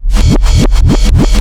REVERSBRK3-R.wav